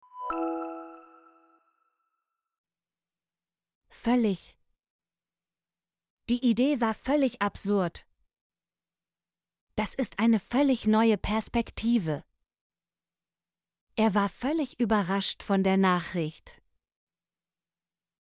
völlig - Example Sentences & Pronunciation, German Frequency List